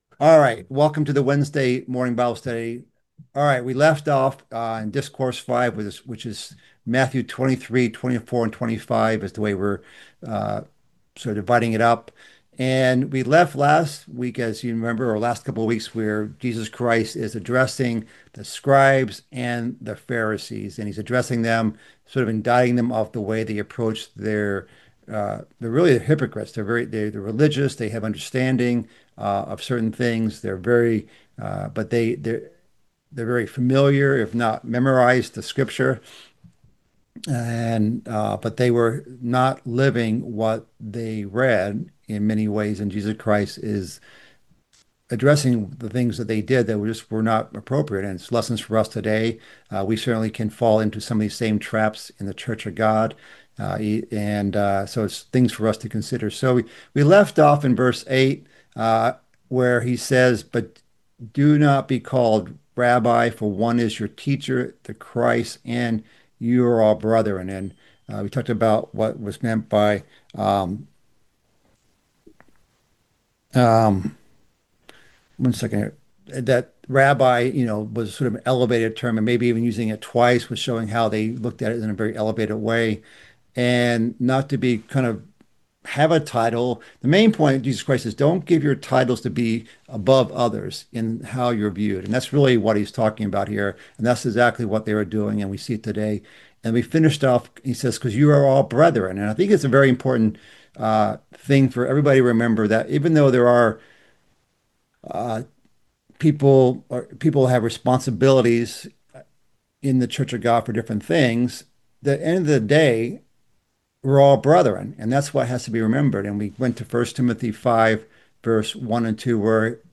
This is the third part of a mid-week Bible study series covering Christ's fifth discourse in the book of Matthew. It covers the first woe to the scribes and Pharisees.